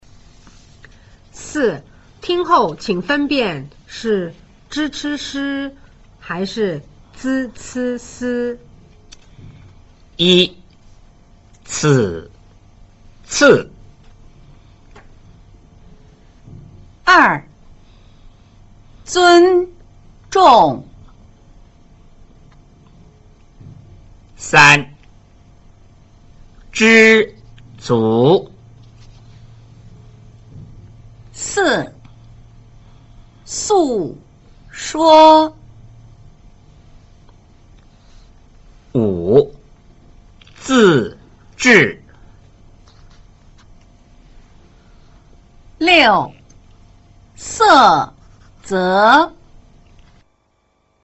4. 聽後請分辨是 zh ch sh 還是 z c s